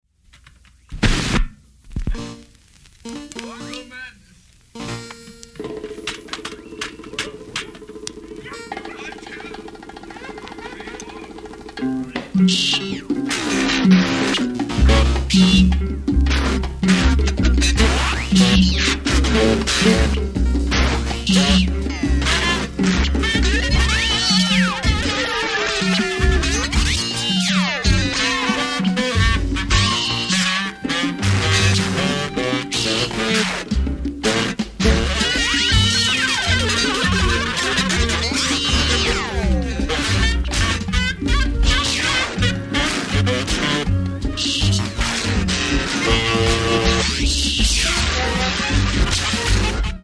Buchlaシンセサイザーもガラクタも面白い音を出すための道具として彼らにとって同等だったようだ。
CalArts電子音楽スタジオでの録音だけではなく、日常的に作っていた奇妙な音群から厳選した内容のレコードだ。